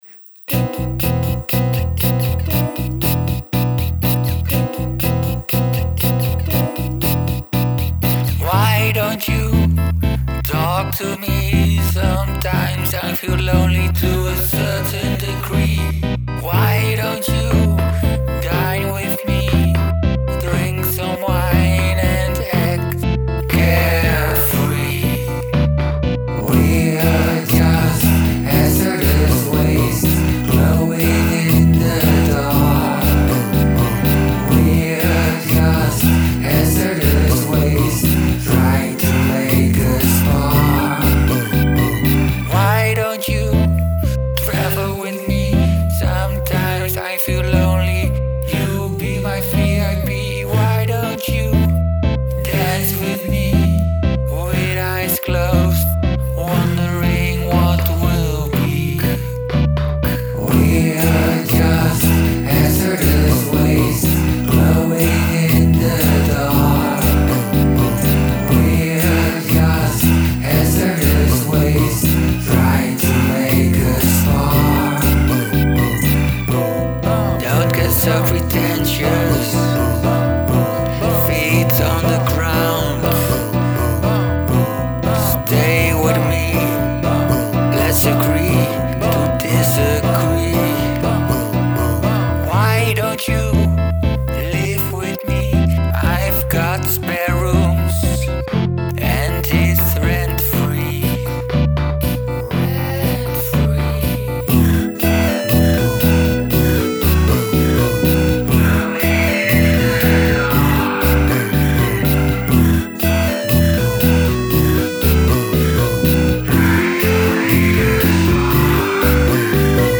Beatbox